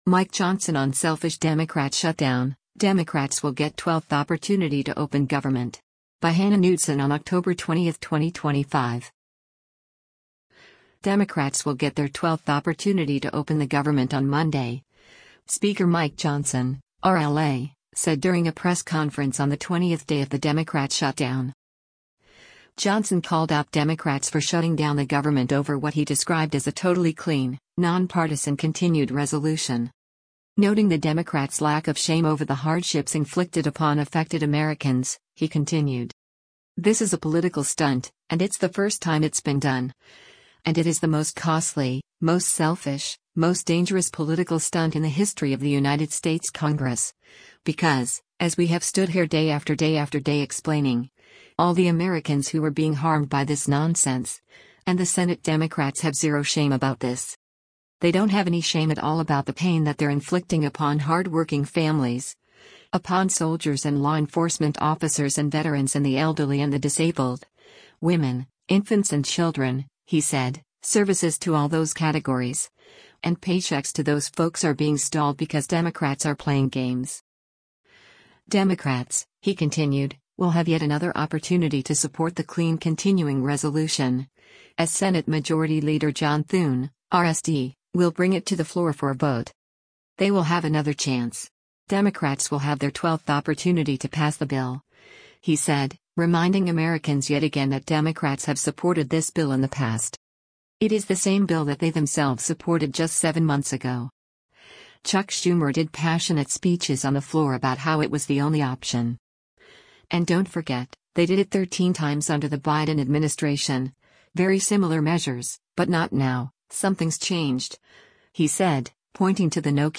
Democrats will get their 12th opportunity to open the government on Monday, Speaker Mike Johnson (R-LA) said during a press conference on the 20th day of the Democrat shutdown.